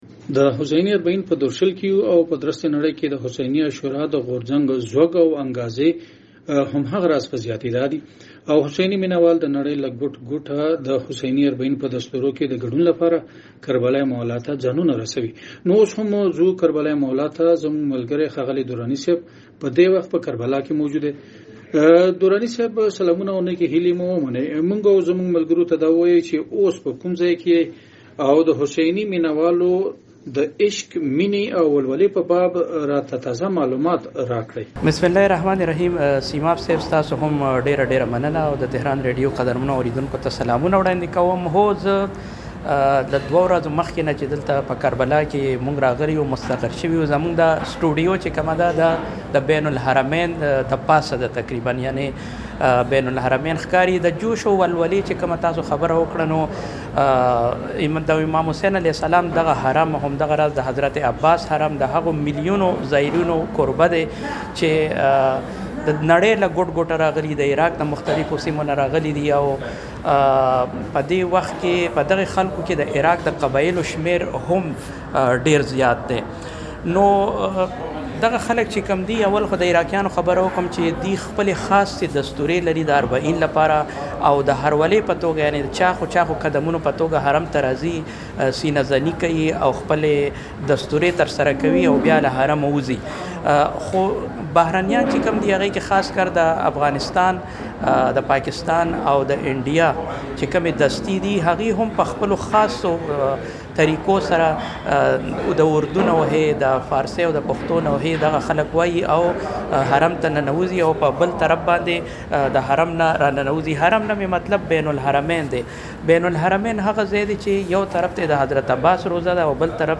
همداراز په کربلا کې  په تازه حالاتو باندې موجود د تهران ریډیو خبریال سره شوي مرکه ستاسو خدمت ته وړاندې کیږي.